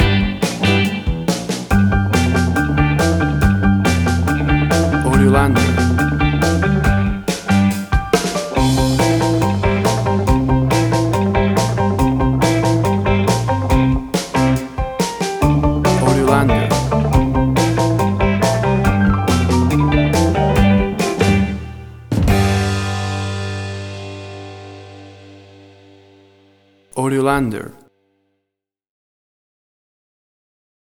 Tempo (BPM): 112